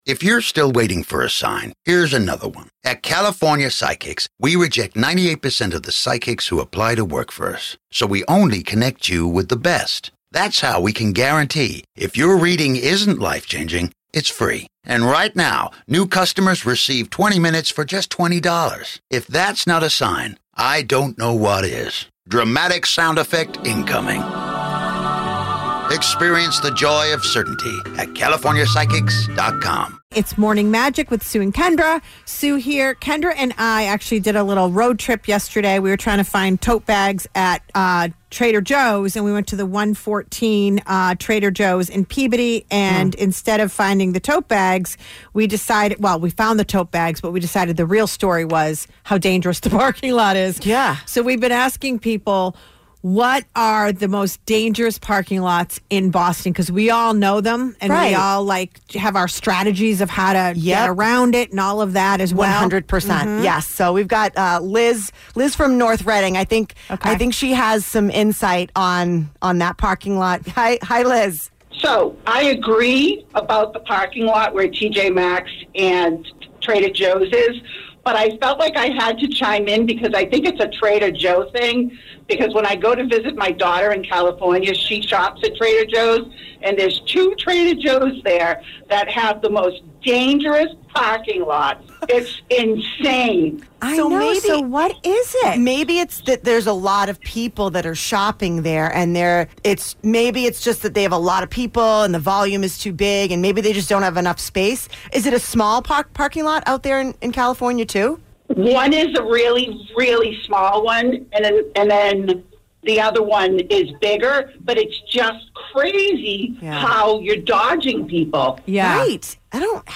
The MAGIC Listeners Call In About Bad Parking Lots 2025-04-15